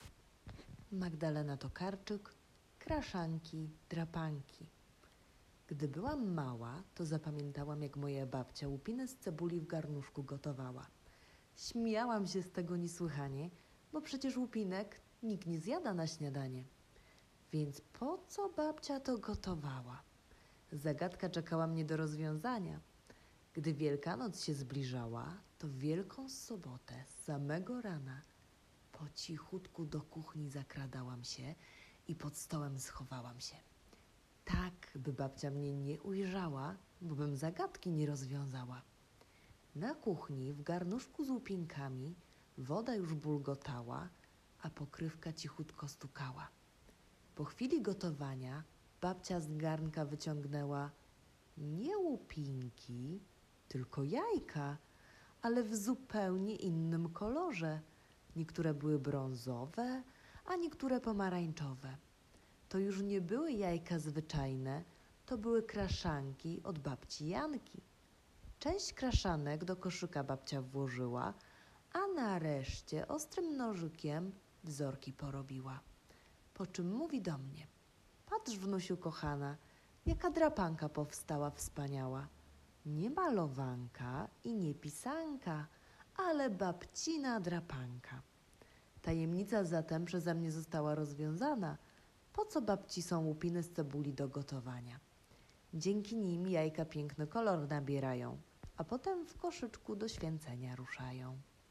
1. Wysłuchajcie tekstu „Kraszanki – drapanki” czytanego przez nauczyciela.